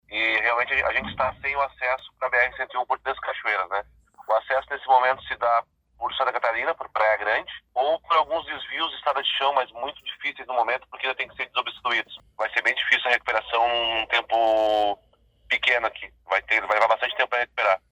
Sete prefeitos ouvidos pela reportagem da Guaíba relatam os dramas enfrentados pelas diferentes regiões do Estado
Em Morrinhos do Sul, no litoral Norte, houve o desabamento de uma ponte na ERS 494, obrigando os veículos a fazerem um desvio e ingressarem na cidade Santa Catarina, conforme explica o prefeito Marcos da Silveira.